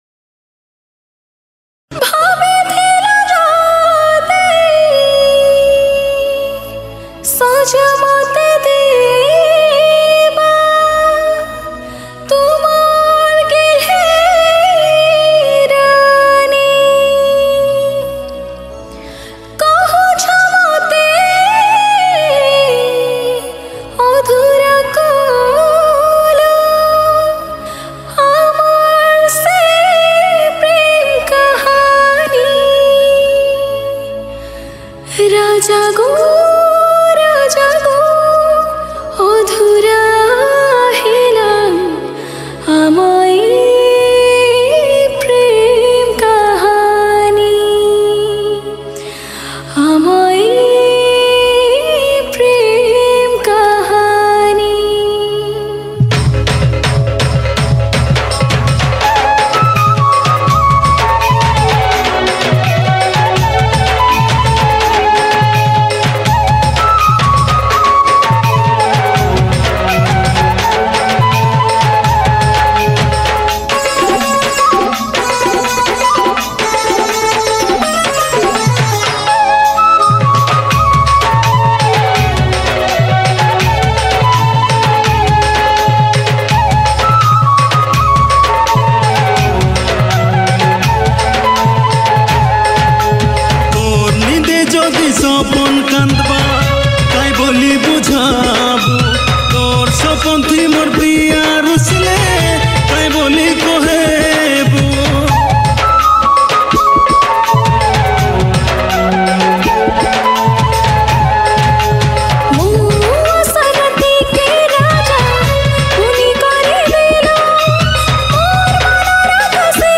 New Sambalpuri